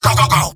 Robot-filtered lines from MvM. This is an audio clip from the game Team Fortress 2 .
{{AudioTF2}} Category:Engineer Robot audio responses You cannot overwrite this file.